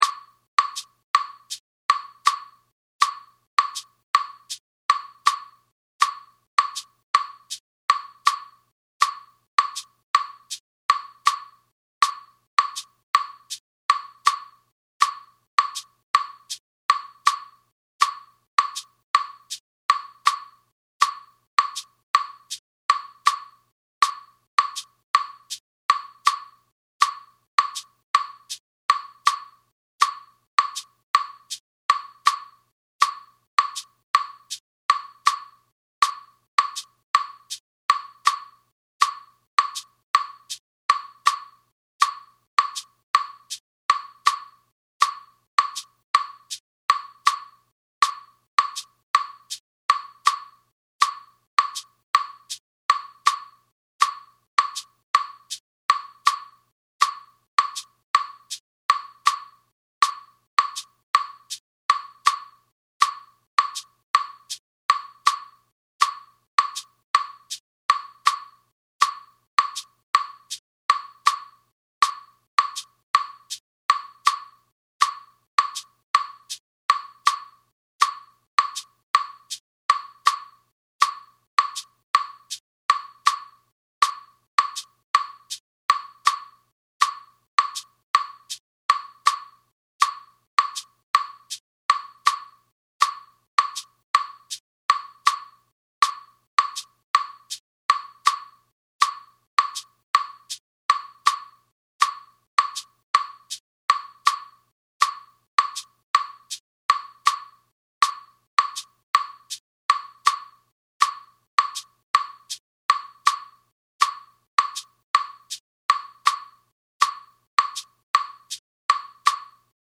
SON CLAVÉ – An important rhythmic concept in African and Afro-Caribbean music.
4/4 Son Clavé
audio (with shekeré)
son-clave-Slow-FREE.mp3